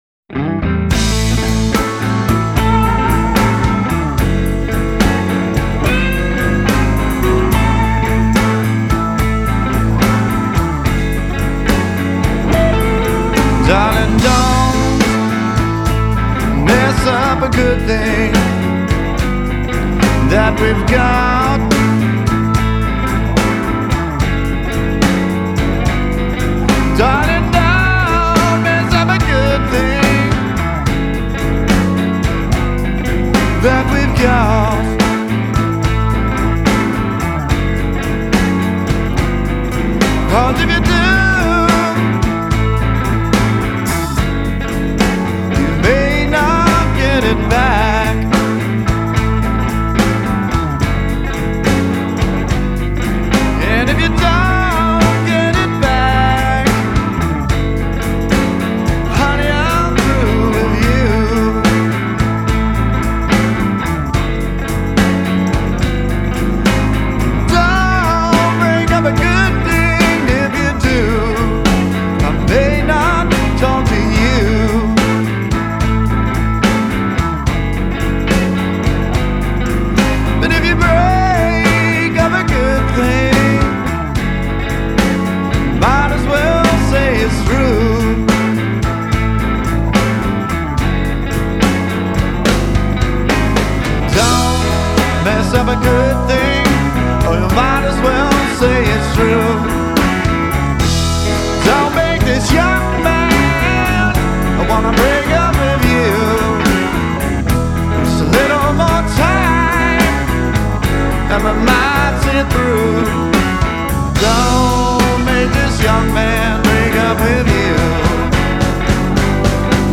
offers up a gutsy, yet classy sound